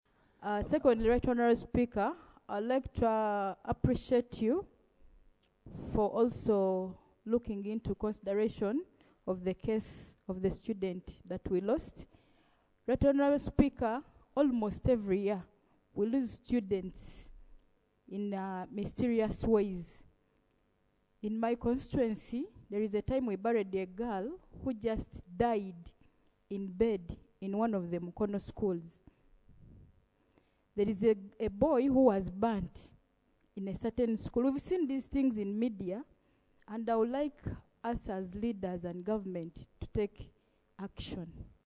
Hon. Brenda Namukuta (NRM, Kaliro District Woman Representative) noted that students die mysteriously in schools every year and called for investigations into the matter.